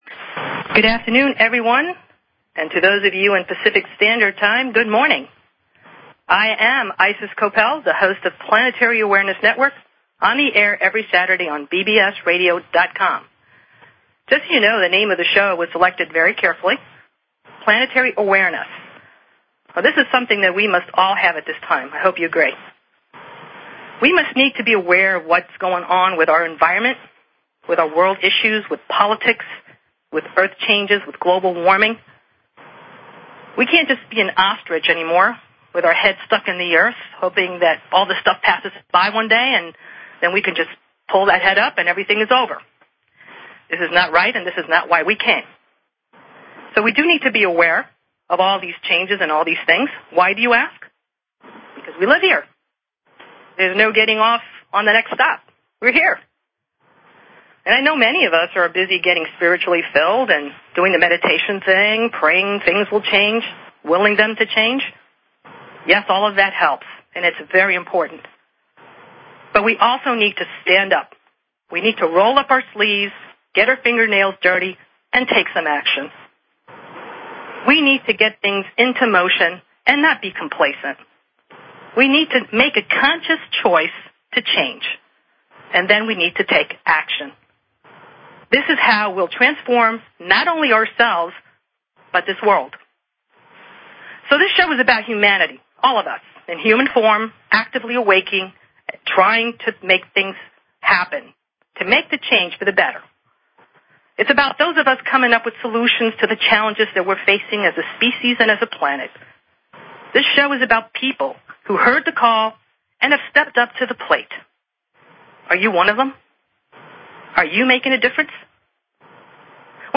Talk Show Episode, Audio Podcast, Planetary_Awareness_Network and Courtesy of BBS Radio on , show guests , about , categorized as